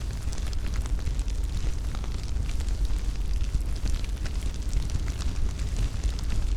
fireloud.ogg